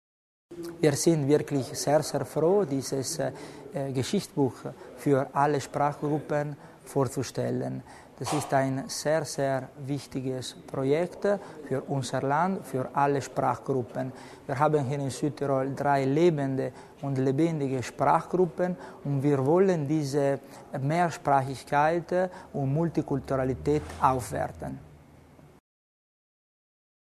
Landesrat Mussner zum Begriff der Kulturenvielfalt